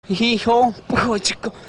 hip-hop.mp3